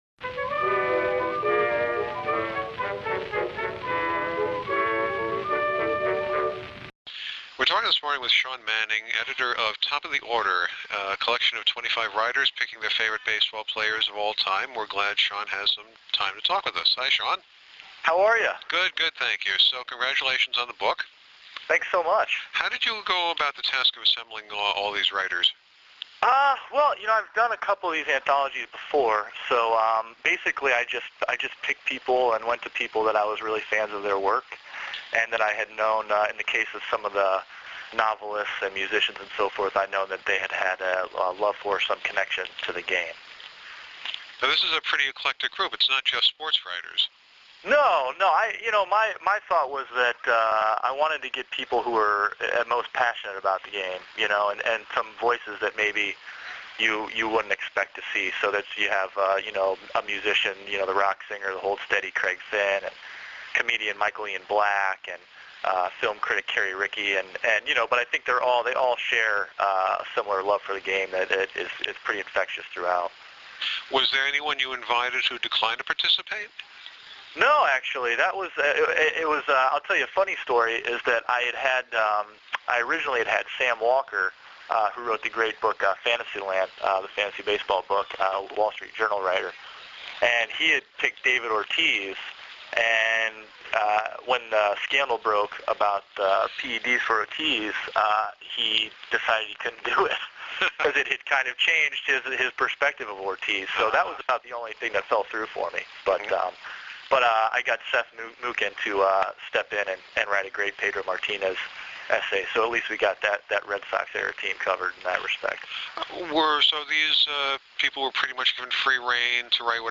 took a few minutes to chat with The Bookshelf about the process of selection, editing, and the joy of putting this jigsaw puzzle together.